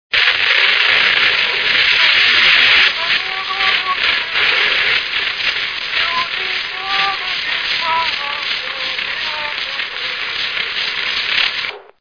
Alföld - Pest-Pilis-Solt-Kiskun vm. - Bogyiszló
ének
Stílus: 7. Régies kisambitusú dallamok